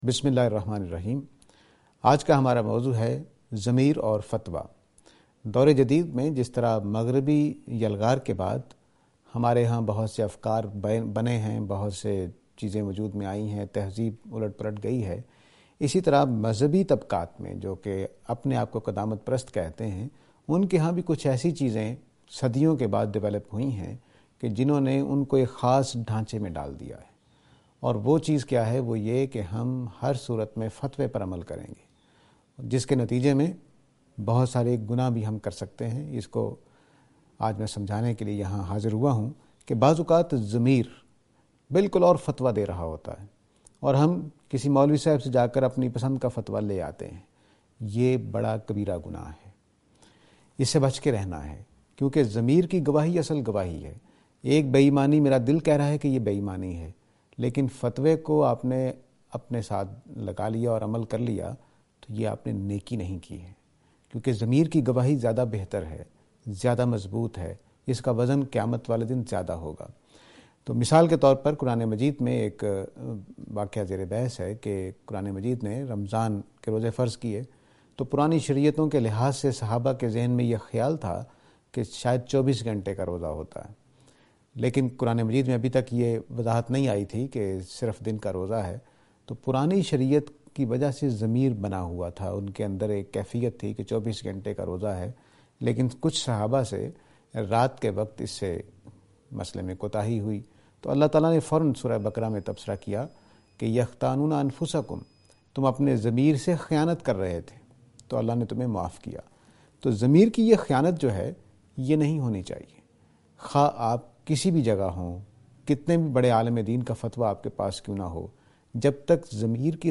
This lecture is and attempt to answer the question "Ḍamīr (Za’meer) and Fatwa".